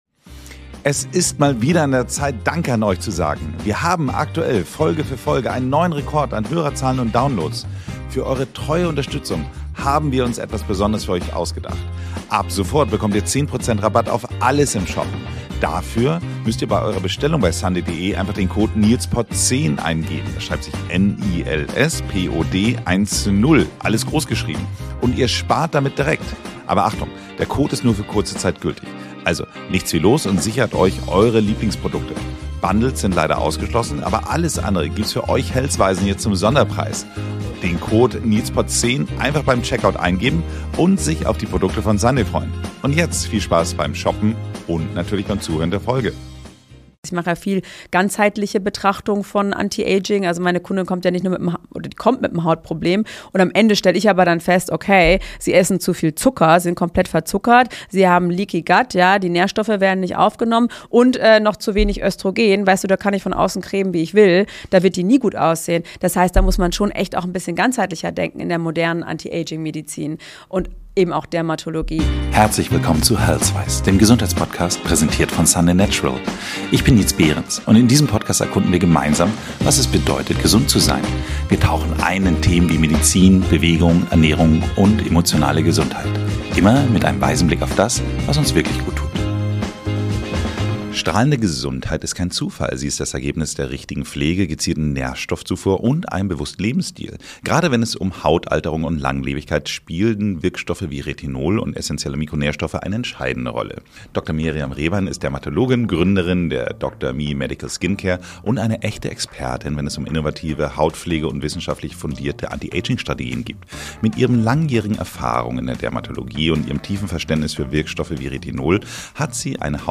Willkommen bei HEALTHWISE, dem Podcast präsentiert von Sunday Natural, der sich intensiv mit der Frage auseinandersetzt, was wahre Gesundheit in unserer modernen Gesellschaft bedeutet. Jede Episode bietet wertvolle Einblicke und inspirierende Gespräche mit Expert*innen aus verschiedenen Gesundheitsbereichen.